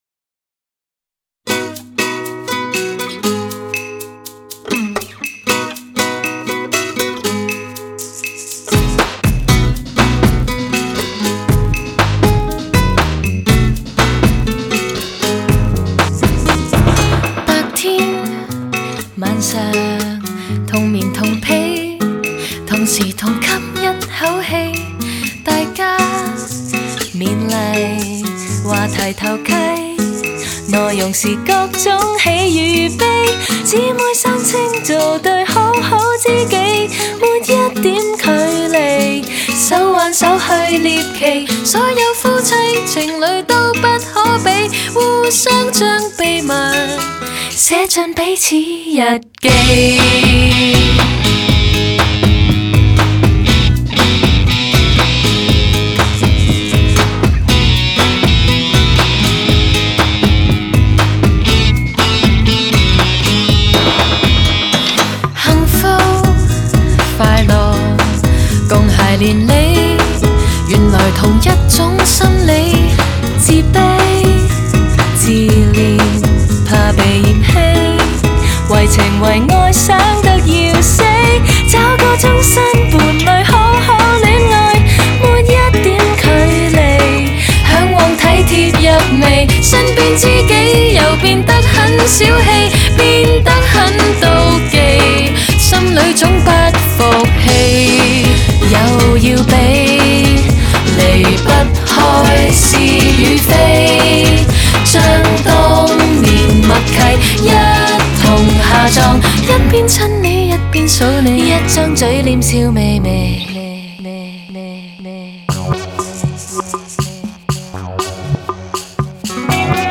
专辑语种：粤语专辑1CD